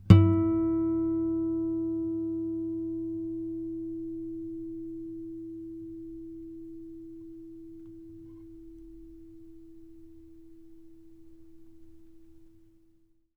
ostinato_and_interrupt / samples / strings_harmonics / 5_harmonic / harmonic-03.wav
harmonic-03.wav